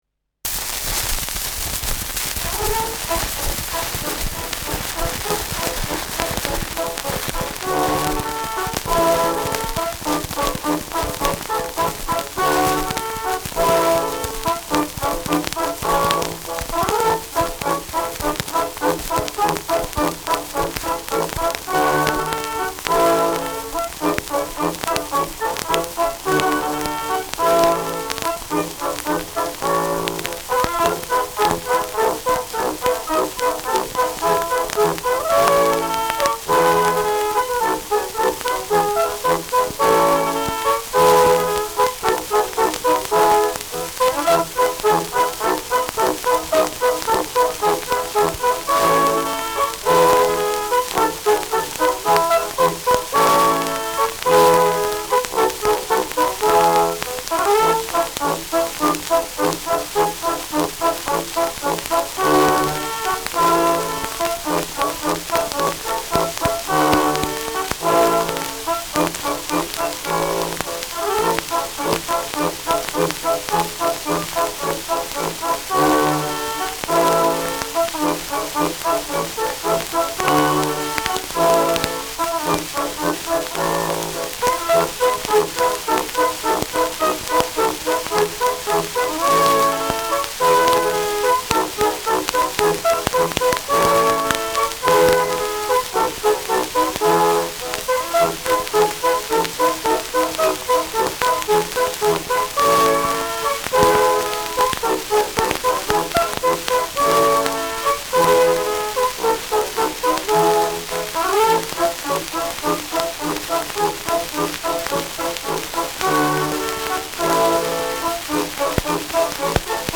Schellackplatte
starkes Rauschen : abgespielt : leiert : starkes Knistern : Nadelgeräusch : häufiges Knacken